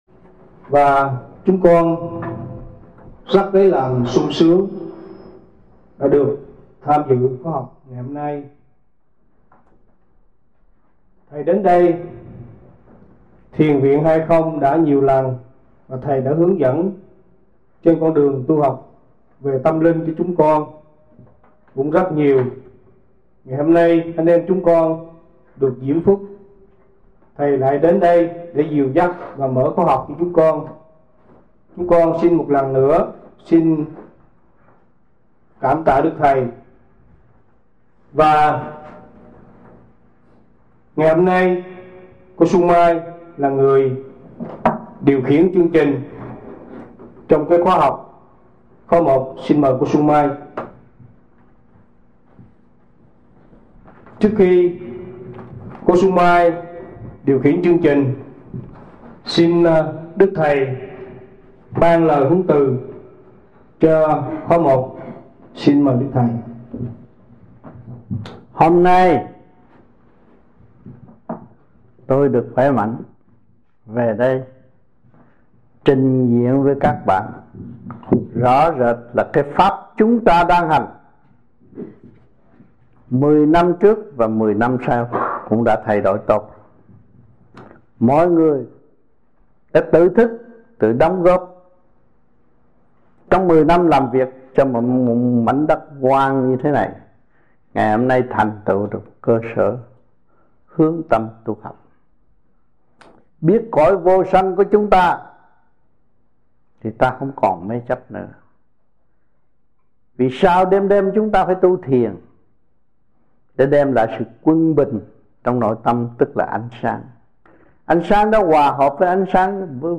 1995-11-07 - Thiền Viện Hai Không - Thuyết Pháp 1